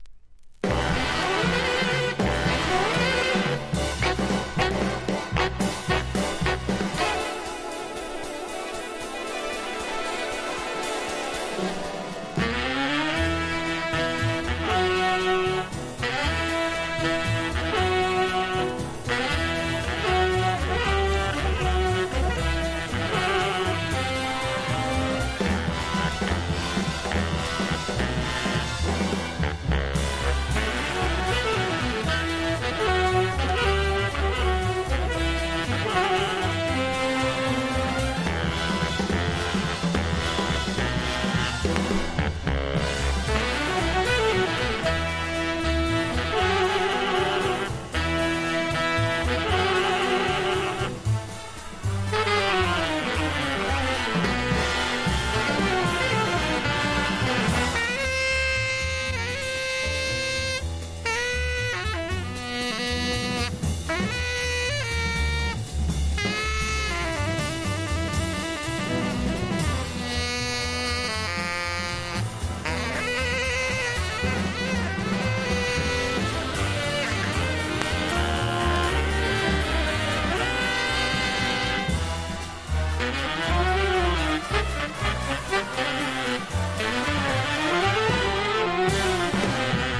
• JAZZ
67年のニューポート・ジャズ・フェスティバルで演奏し、絶賛を浴びたナンバーを帰国後にスタジオで録音した作品。
和ジャズ